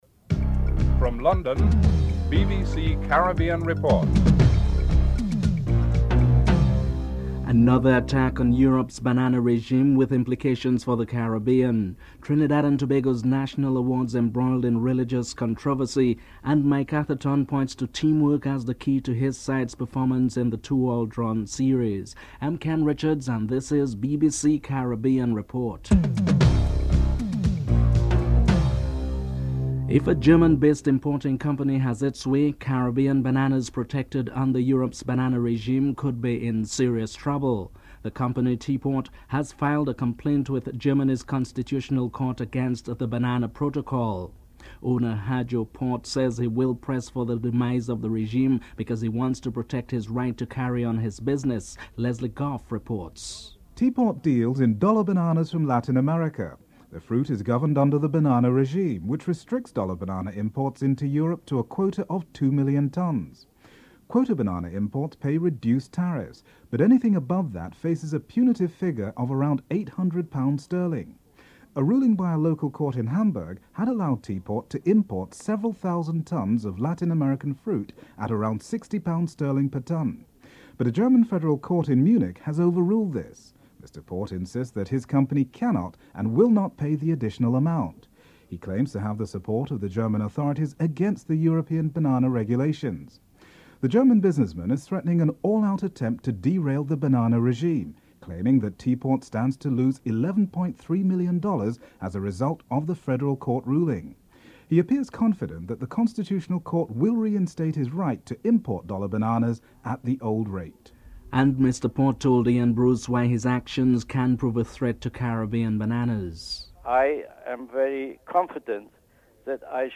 The British Broadcasting Corporation
8. Recap of top stories (14:45-15:10)